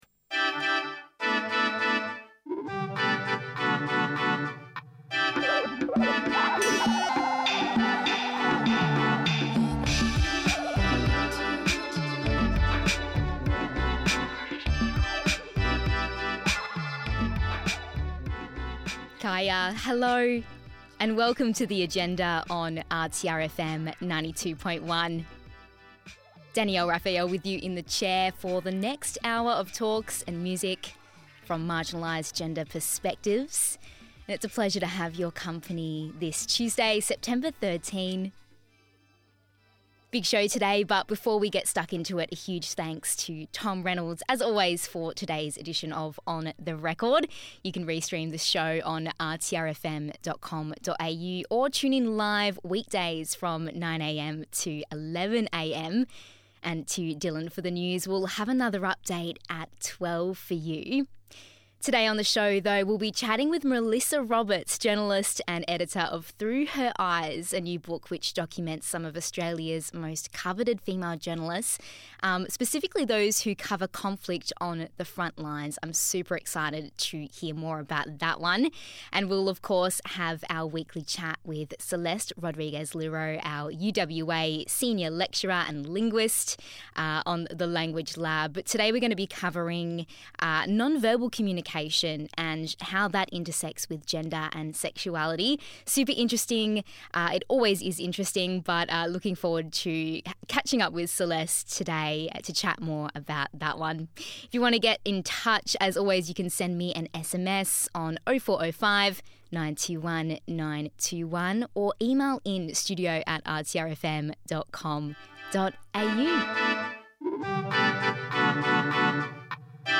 jumps into the studio for this week’s edition of The Agenda!